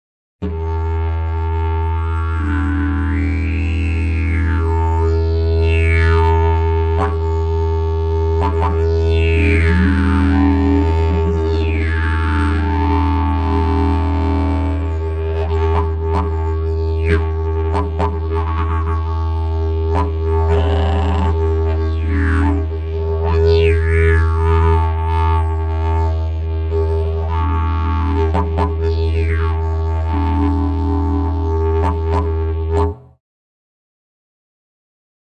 Utilizzando la spinta dalla posizione fonetica /o/ alla /i/ (come mostrato in immagine) si crea una serie di armonici dal più basso al più alto simili al suono onomatopeico “wok”.
Suono particolarmente udibile e con una discreta dinamica.
Sample n°18 contiene: applicazione del wok su nota base.